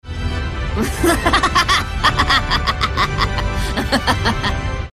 Sound Buttons: Sound Buttons View : Lumine Laugh